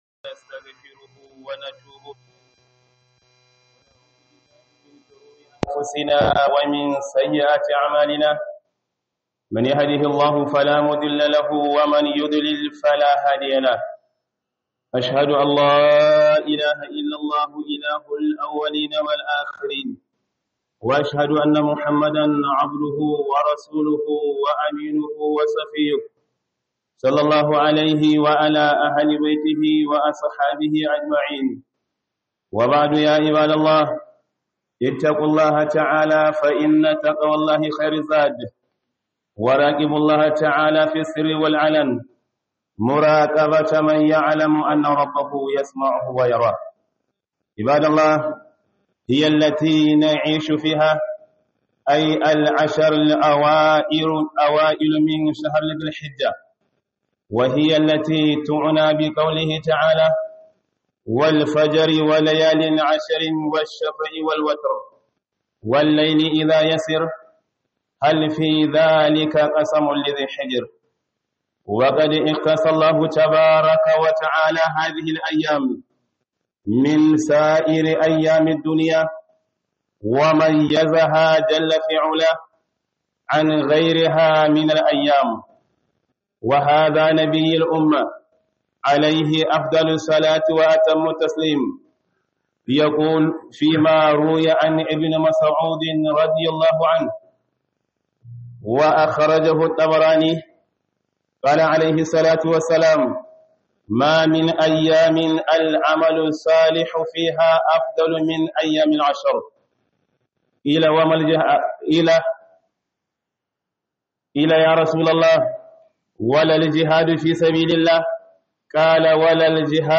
Khudubar Jibwis Low-cost Ningi - Azumin ranar Arfa